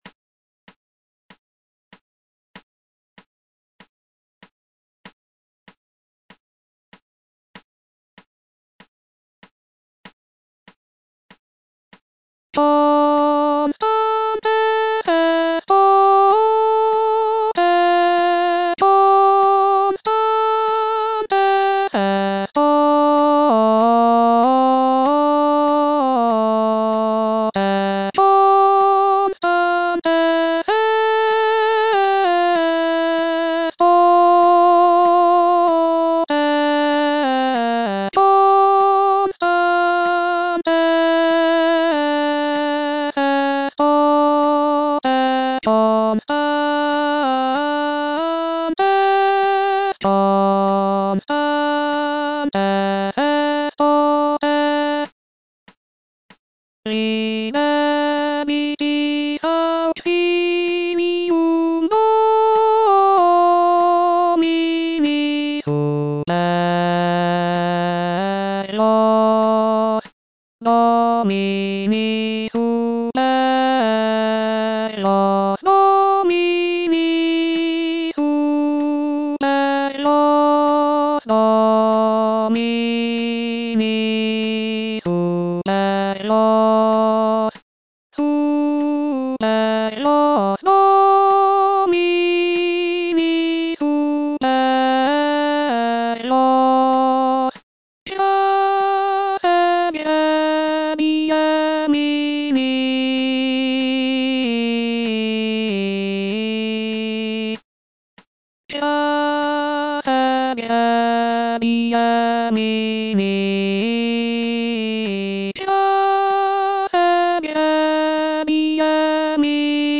H. DE LA COURT - JUDAEA ET HIERUSALEM NOLITE TIMERE Constantes estote - Alto (Voice with metronome) Ads stop:
The composition is a sacred motet, typically performed by a choir.